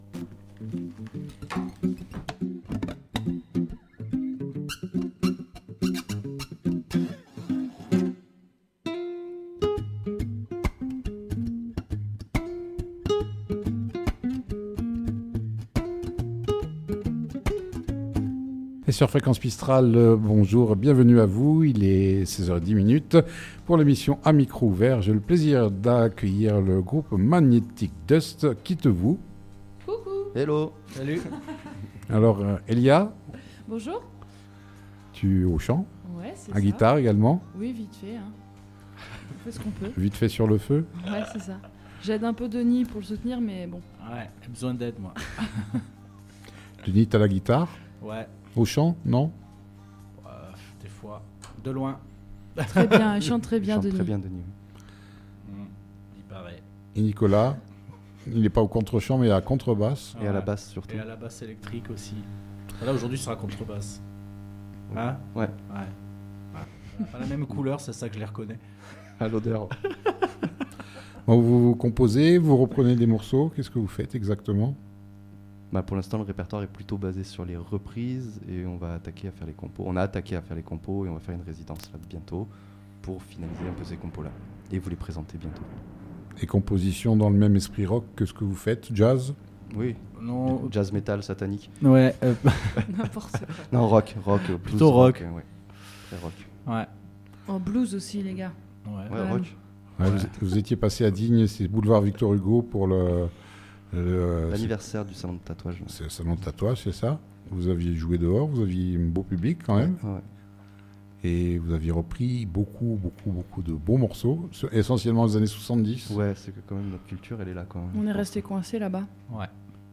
étaient en direct
ils sont venus en formation chant, guitares et contrebasse !